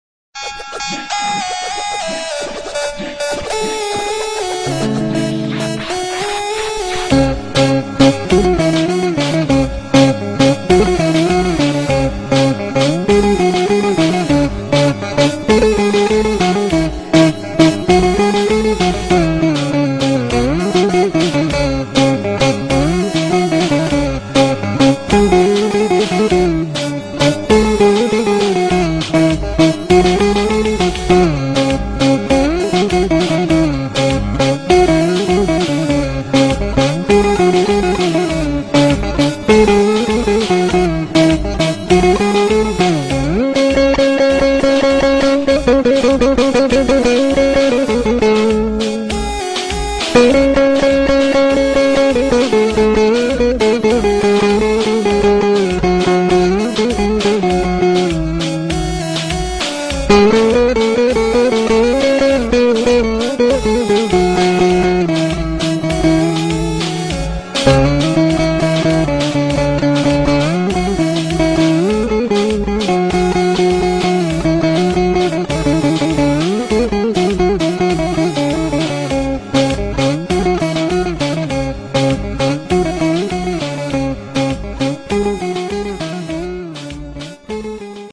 Elektro Baglama ile RUMBA Buyrun Dinleyin :)
baglama il rumba nasil calinir gorelim :D
baglama-rumba-mp3.3529